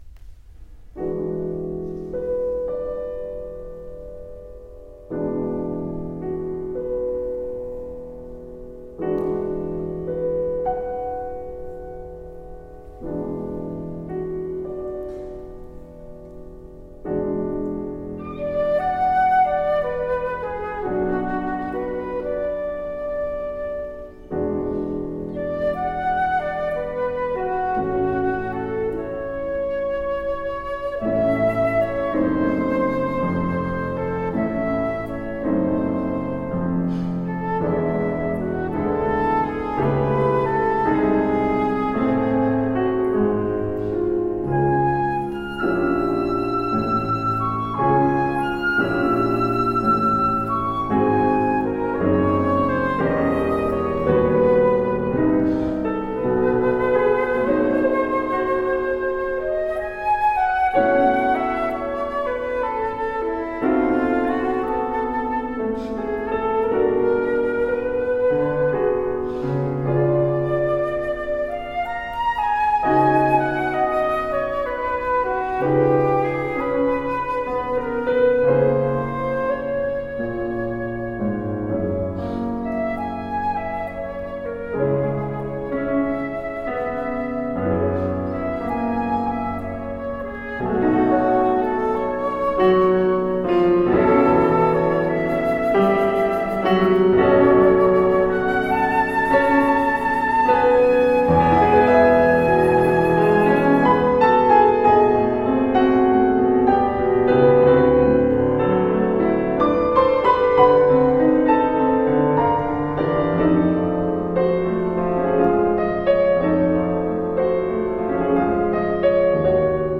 Tagged as: Classical, Instrumental Classical, Flute